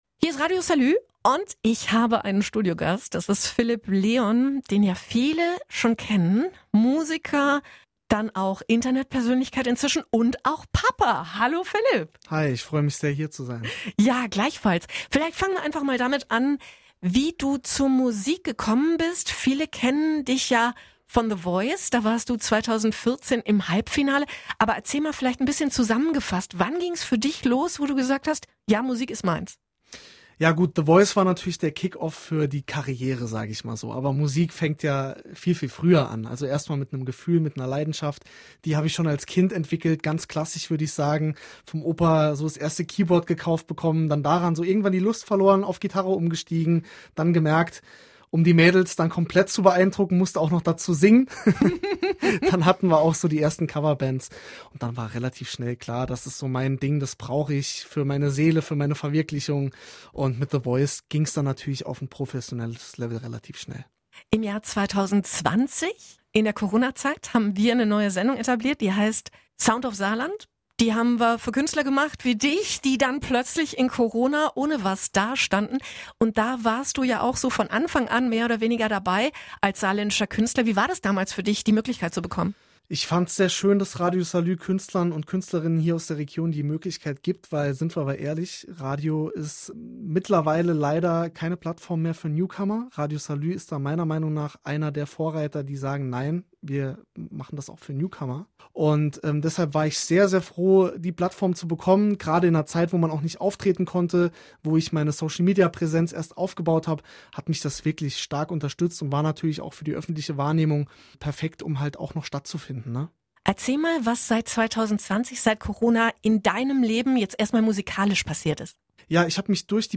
Ein persönliches Gespräch mit dem saarländischen Musiker und